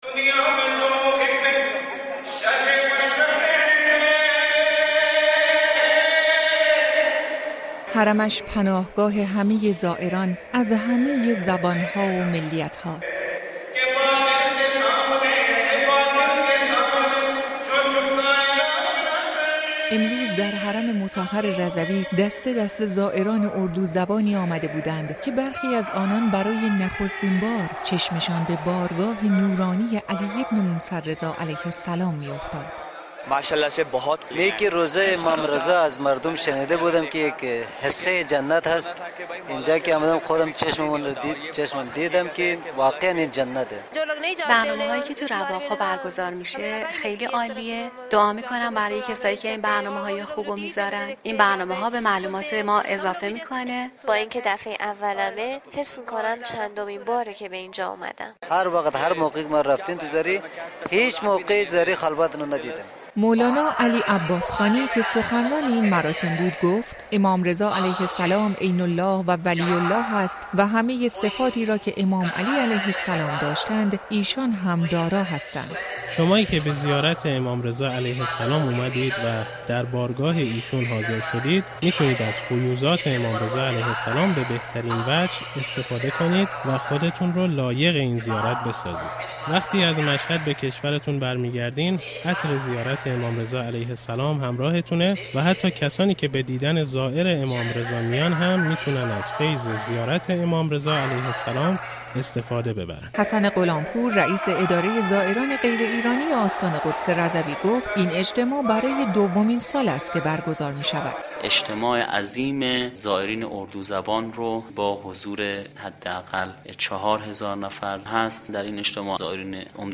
با گذشت 4 روز از شهادت امام رضا(ع)، مشهدالرضا مملو از جمعیت عزادار اردو زبانی است که در رواق امام خمینی حرم مطهر رضوی حضور یافتند و اجتماع با شکوهی را به تصویر کشاندند. هزاران زائر عزادار اردو زبان هند و پاکستان که از سفر حرمین شرفین کربلای معلی به مشهد مقدس مشرف شده بودند، با اجتماع در این رواق به عزاداری و نوحه‌خوانی و اجرای آیین‌های خاص اردو زبانان پرداختند و در صفوفی منظم کنار هم ایستادند و بر سر و سینه ‌زدند.